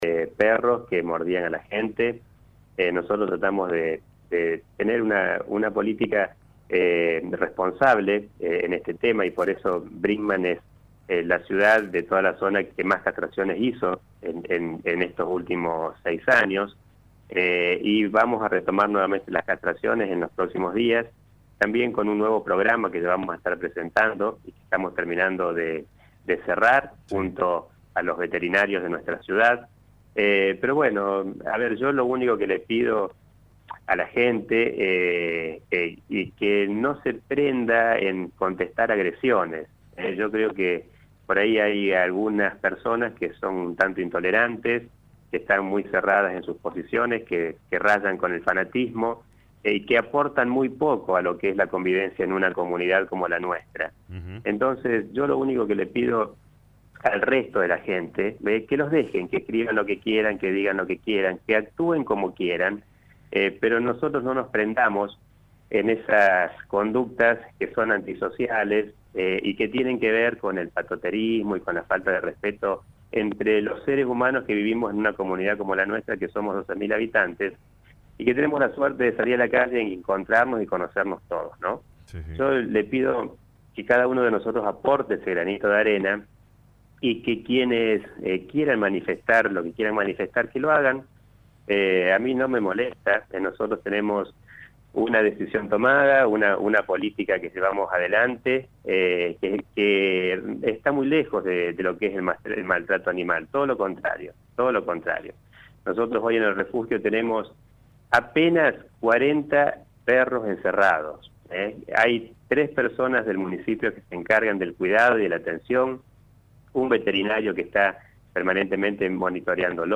El intendente se refirió a la situación actual sobre los perros callejeros de la ciudad.